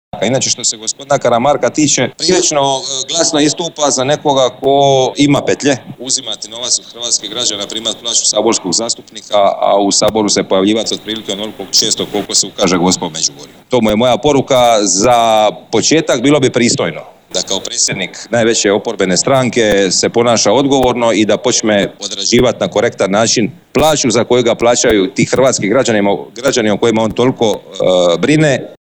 Oštru poruku iz Drniša čelniku najjače oporbene stranke Tomislavu Karamarku uputio je saborski zastupnik Petar Baranović.